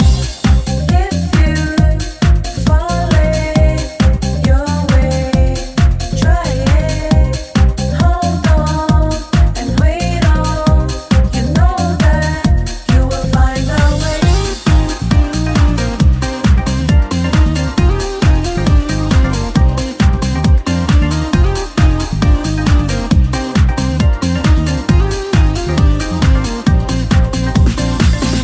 Genere: pop,dance,deep,afro-house,house.hit